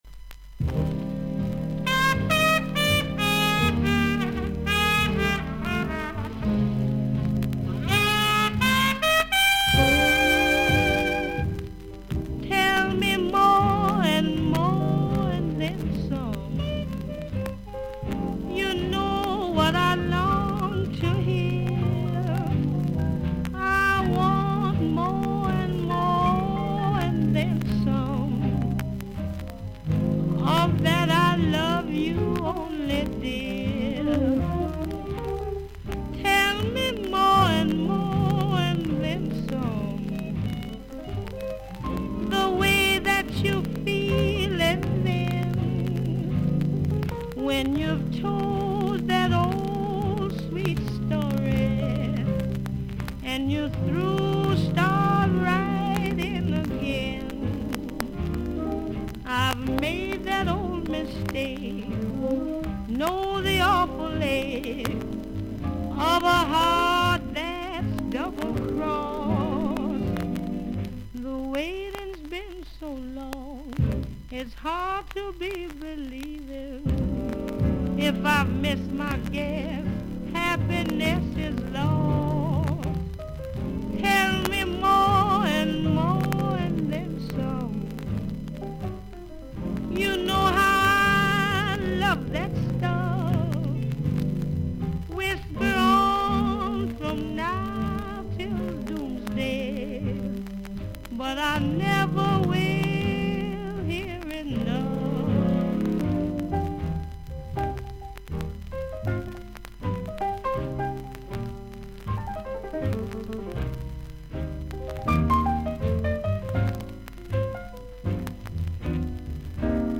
アメリカを代表する女性ジャズ・シンガー。